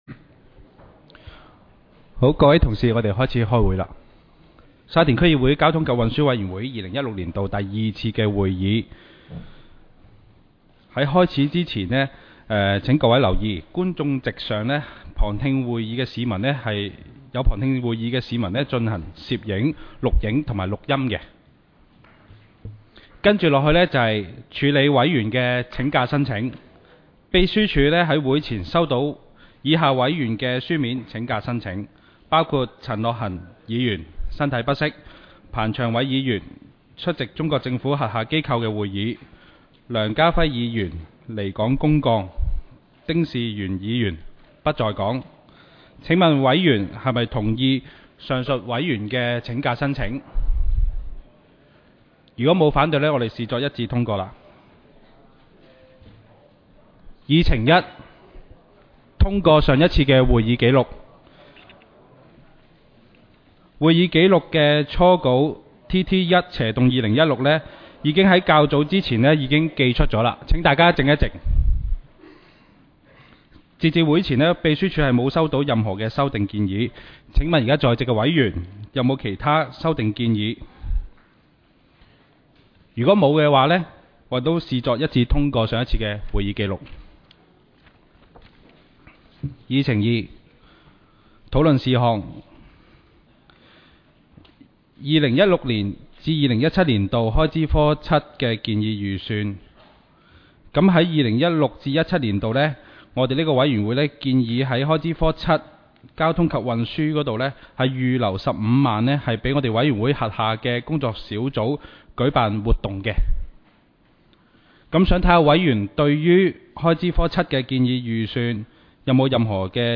委员会会议的录音记录
交通及运输委员会第二次会议 日期: 2016-03-08 (星期二) 时间: 下午2时30分 地点: 沙田区议会会议室 议程 讨论时间 I. 二零一六至二零一七年度开支科7建议预算 00:02:08 II.